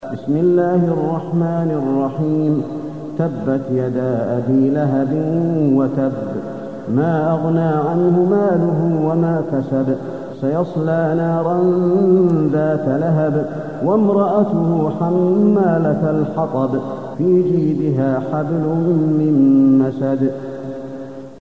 المكان: المسجد النبوي المسد